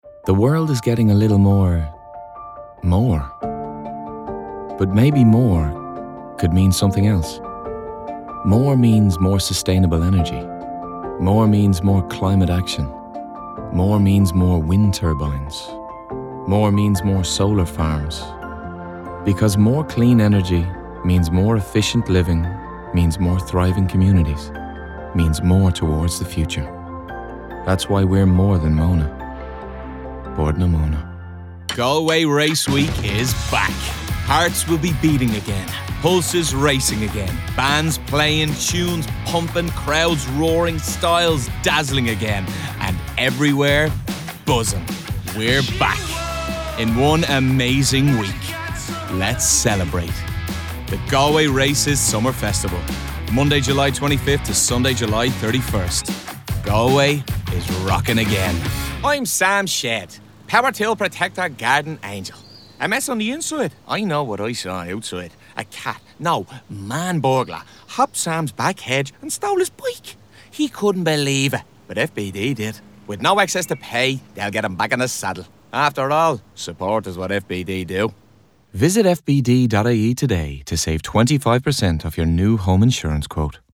Track 1/1: Compilation demo
Male
20s/30s
Irish Neutral